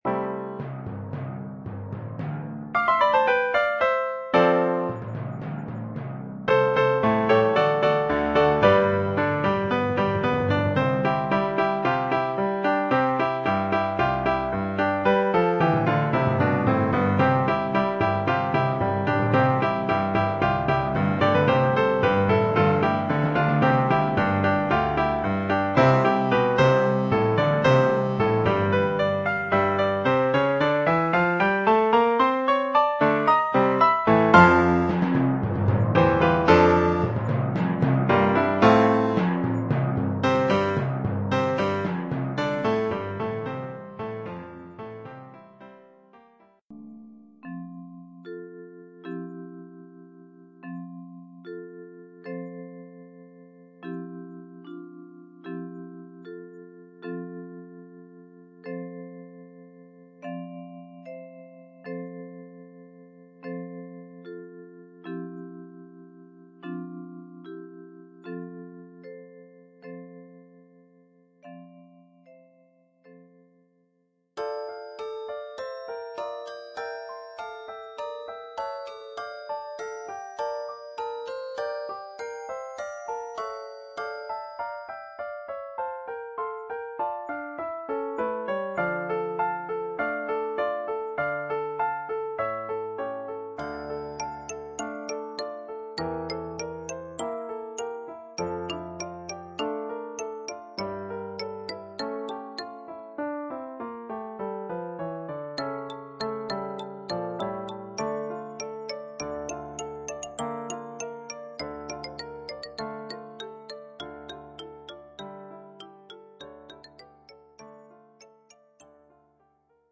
Oeuvre en quatre mouvements,